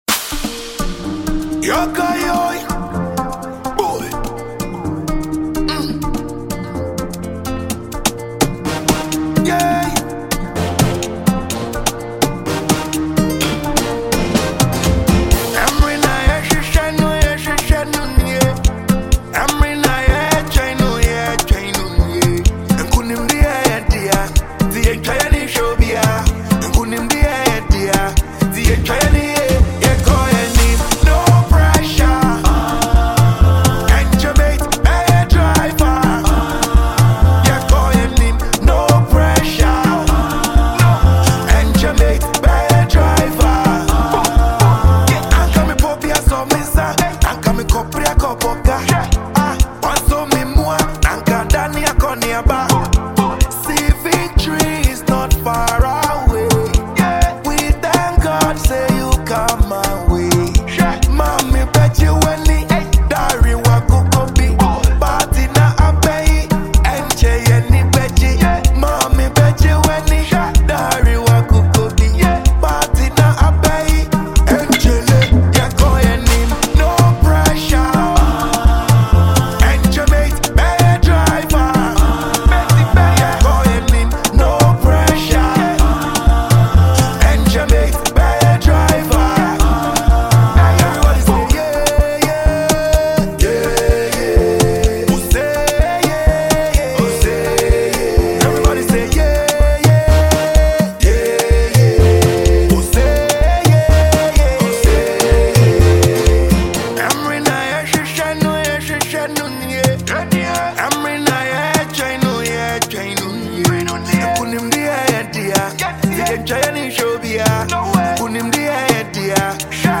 a Ghanaian reggae dancehall act
a danceable tune for you to enjoy everywhere you go.